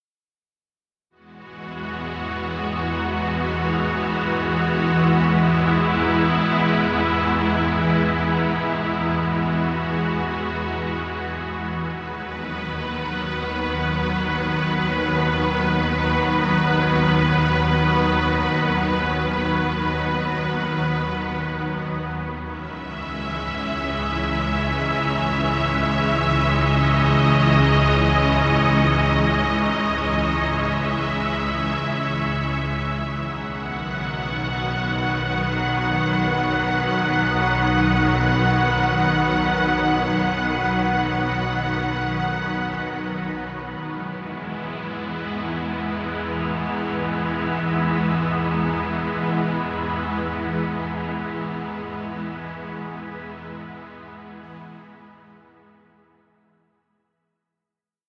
Relax music. Background music Royalty Free.